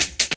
41 HH 01  -R.wav